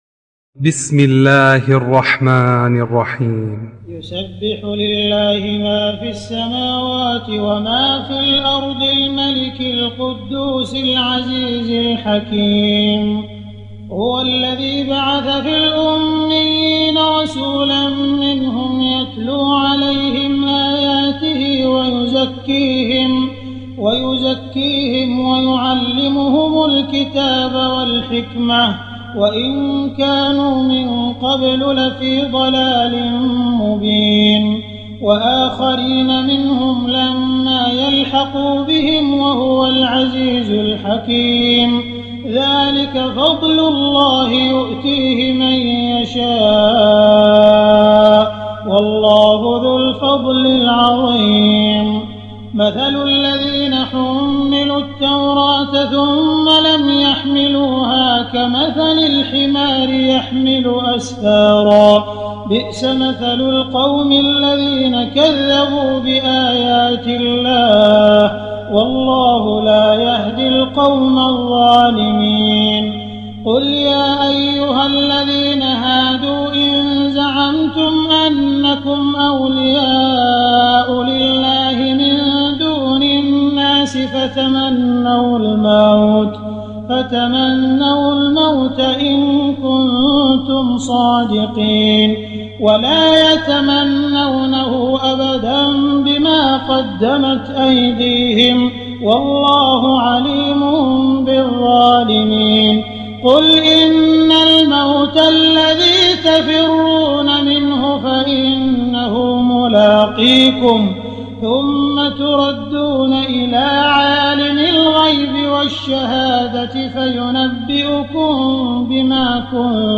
تراويح ليلة 27 رمضان 1419هـ من سور الجمعة الى التحريم Taraweeh 27 st night Ramadan 1419H from Surah Al-Jumu'a to At-Tahrim > تراويح الحرم المكي عام 1419 🕋 > التراويح - تلاوات الحرمين